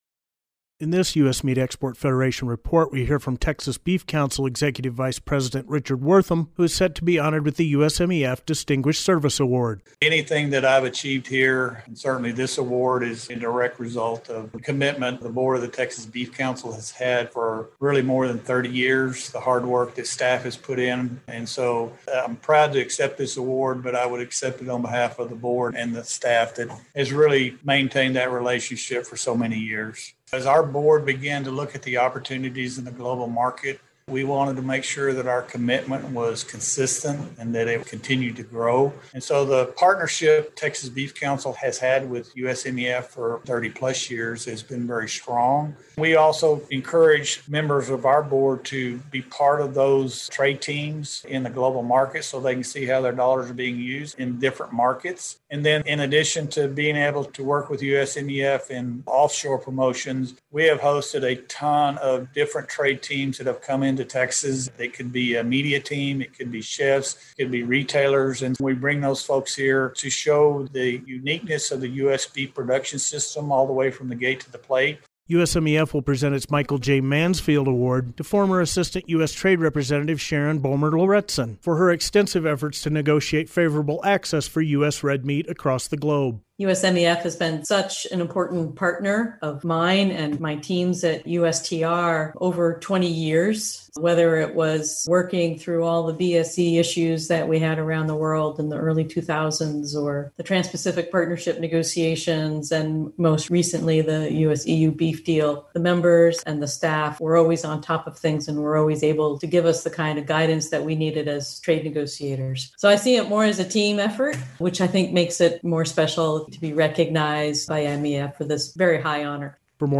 In the attached audio report